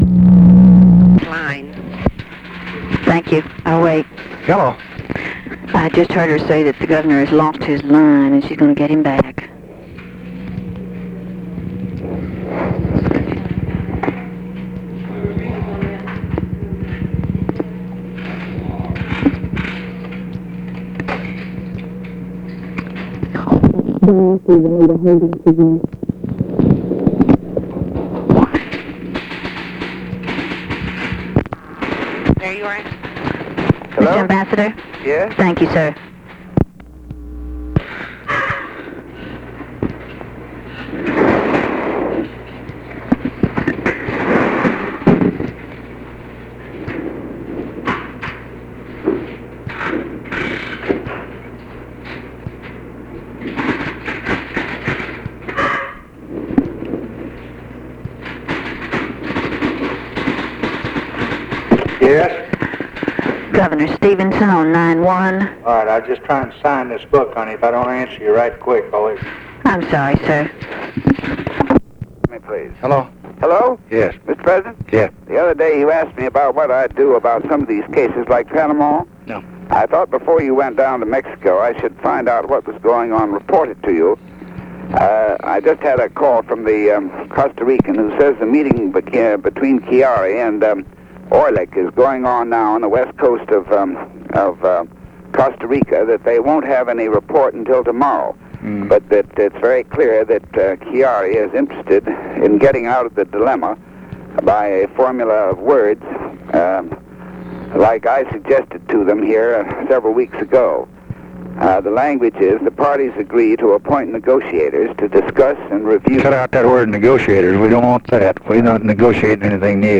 Conversation with ADLAI STEVENSON and OFFICE SECRETARY, February 20, 1964
Secret White House Tapes